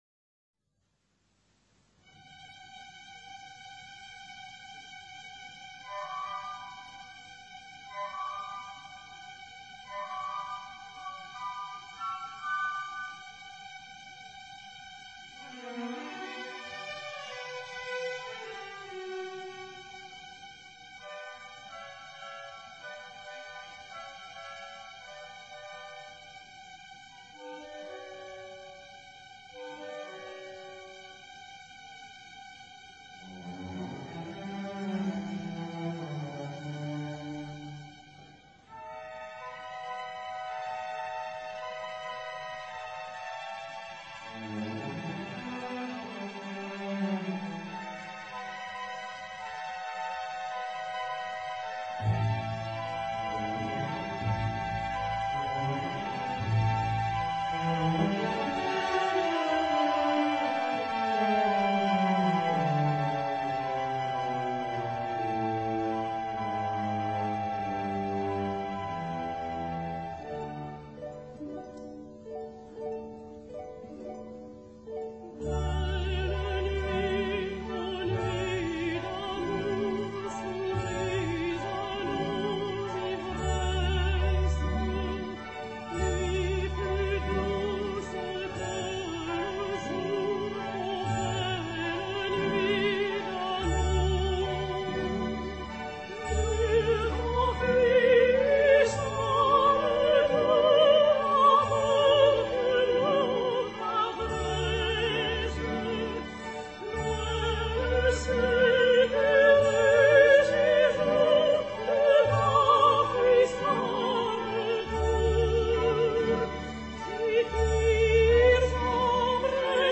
[Soprano]